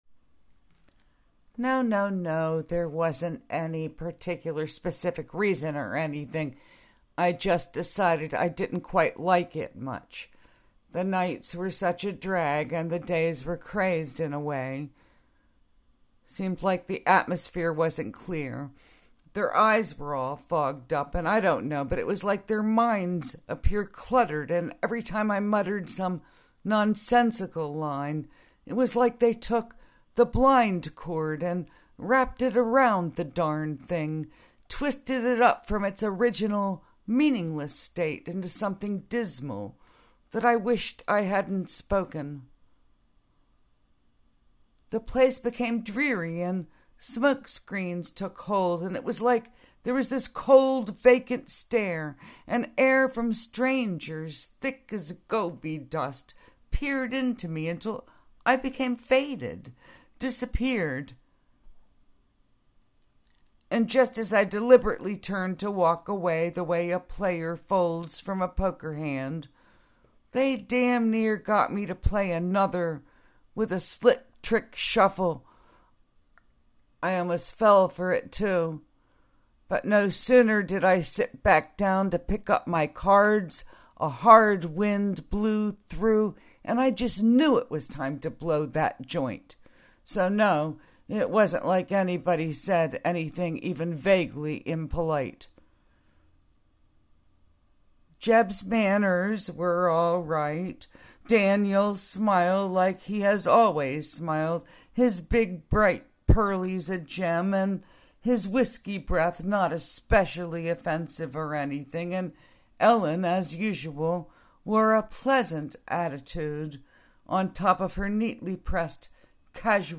Hahahaha! First take... I recorded it
but as the spoken word
But there were a couple of long pauses
But your tone was so perfect.